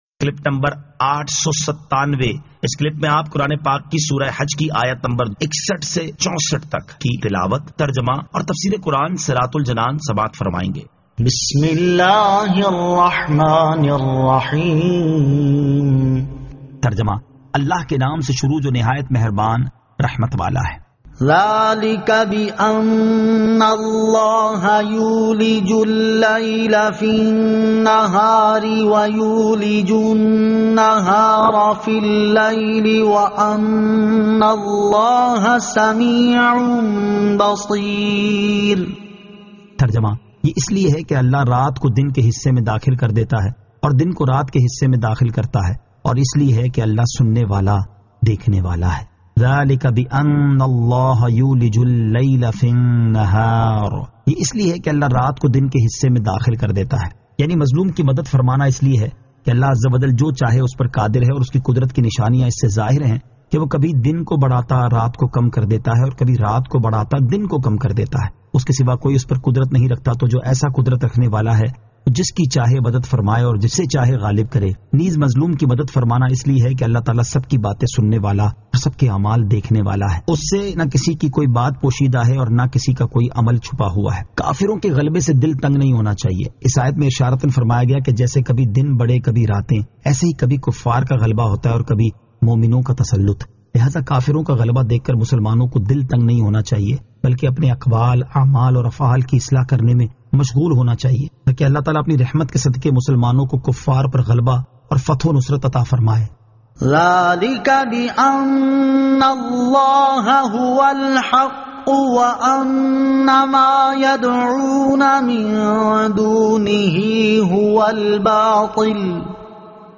Surah Al-Hajj 61 To 64 Tilawat , Tarjama , Tafseer